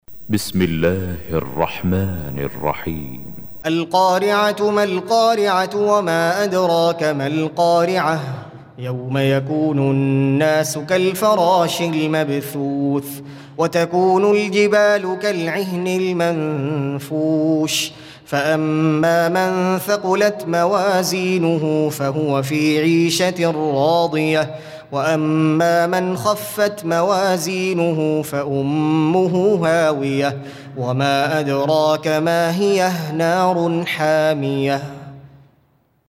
Surah Repeating تكرار السورة Download Surah حمّل السورة Reciting Murattalah Audio for 101. Surah Al-Q�ri'ah سورة القارعة N.B *Surah Includes Al-Basmalah Reciters Sequents تتابع التلاوات Reciters Repeats تكرار التلاوات